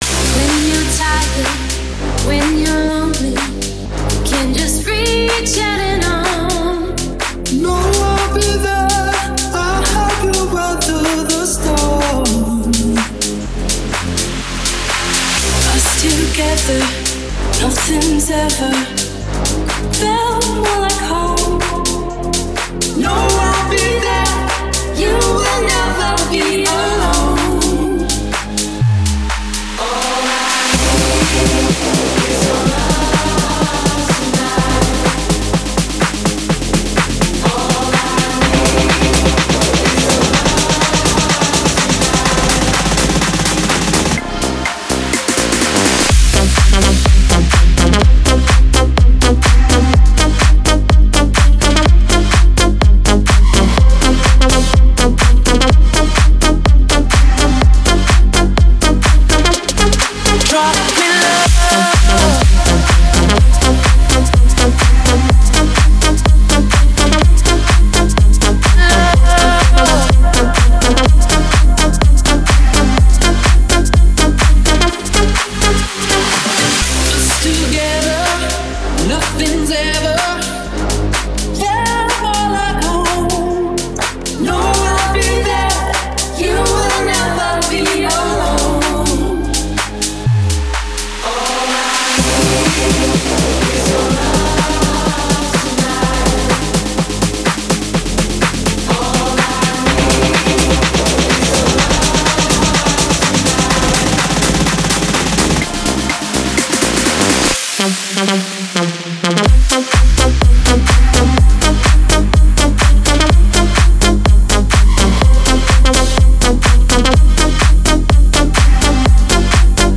Жанр: Club, Dance, Other